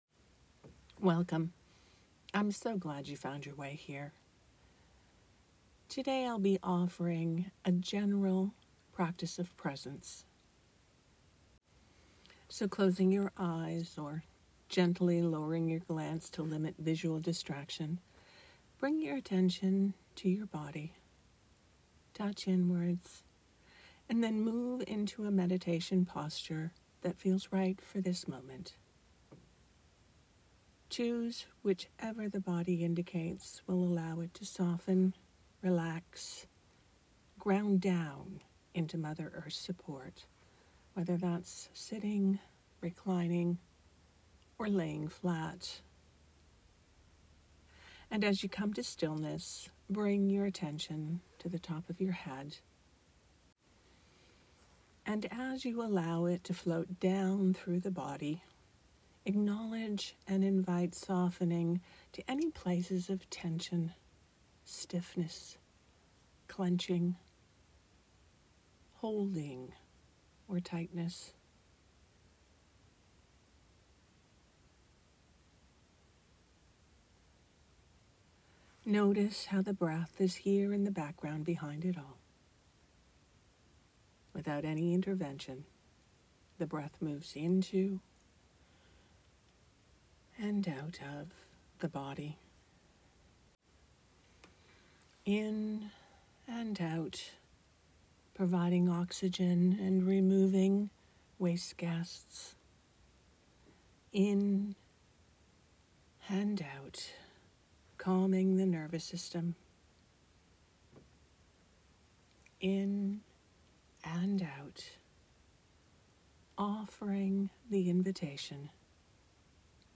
These meditations are offered freely.